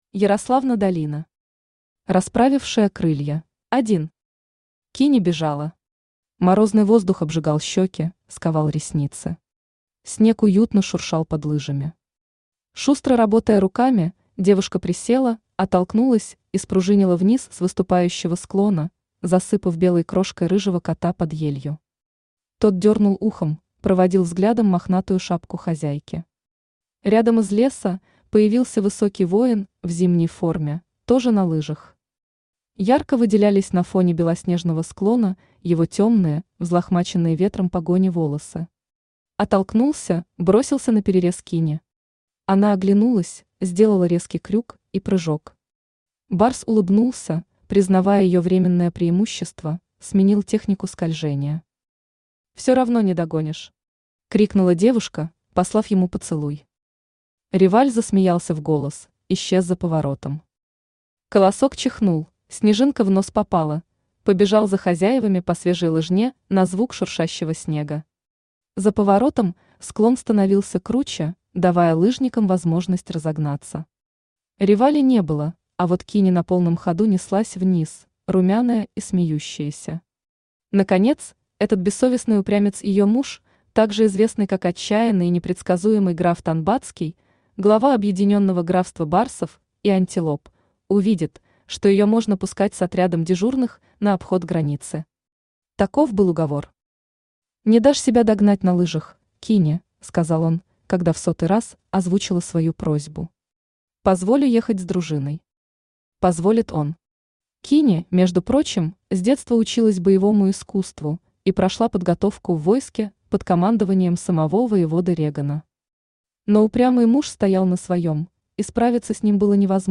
Аудиокнига Расправившая крылья | Библиотека аудиокниг
Aудиокнига Расправившая крылья Автор Ярославна Долина Читает аудиокнигу Авточтец ЛитРес.